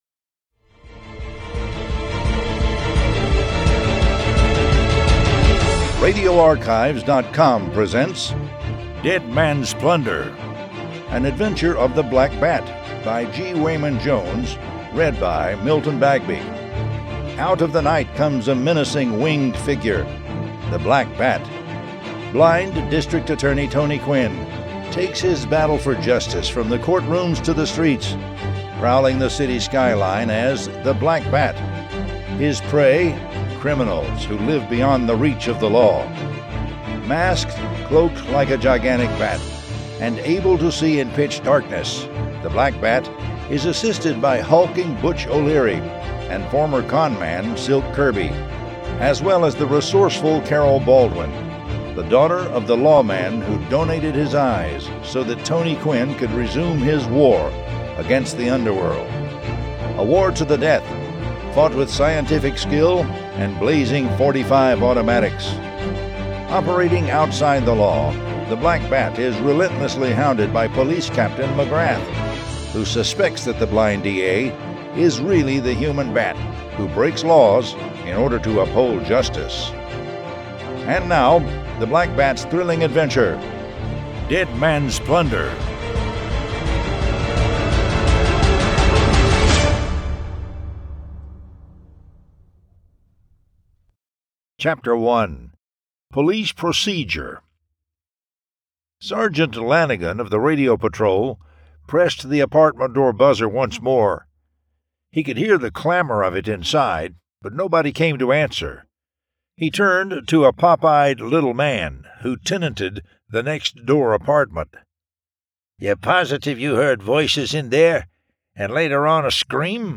The Black Bat Audiobook #42 Dead Man's Plunder